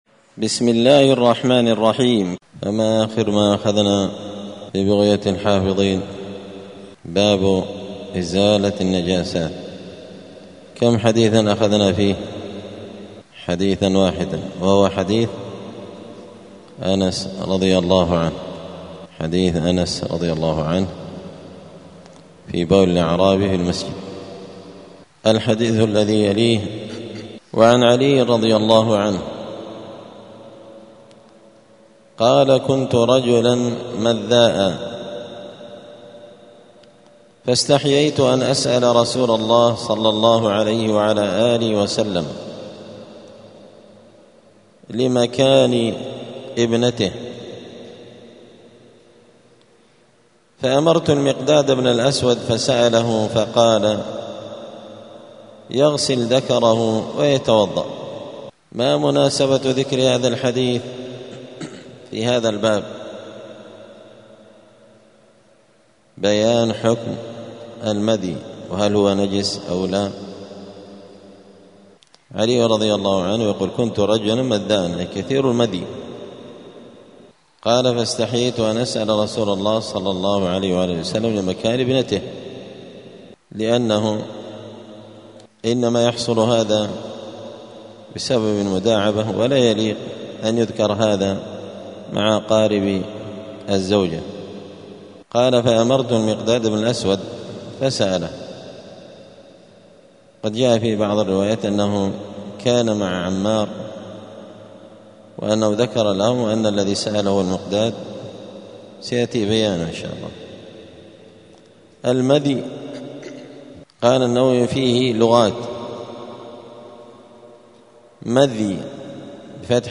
دار الحديث السلفية بمسجد الفرقان قشن المهرة اليمن
*الدرس الثامن بعد المائة [108] {باب إزالة النجاسة حكم الغسل من المذي}*